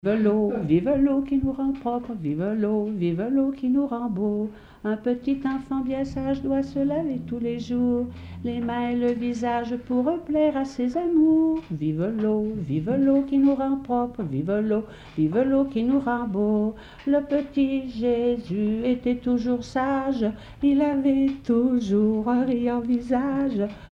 Mémoires et Patrimoines vivants - RaddO est une base de données d'archives iconographiques et sonores.
Suite de rondes enfantines
Enfantines - rondes et jeux
Pièce musicale inédite